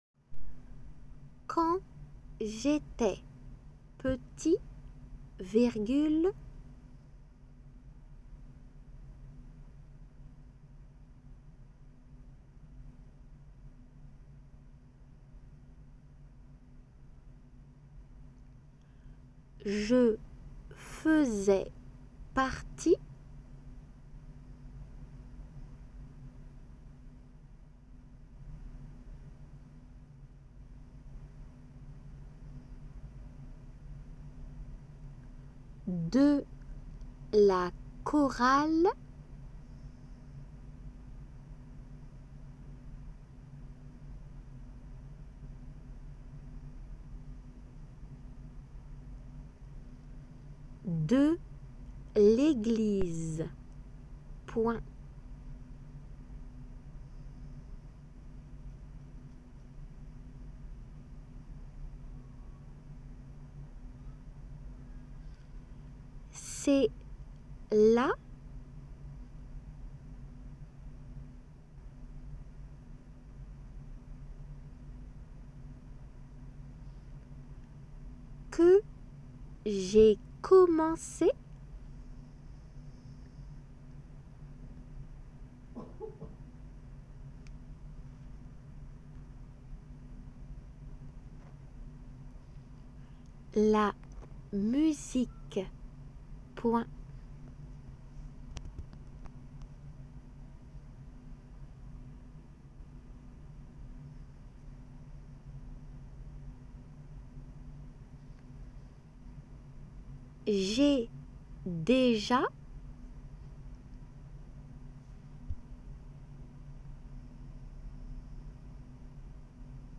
仏検2級　デイクテ　練習 8 音声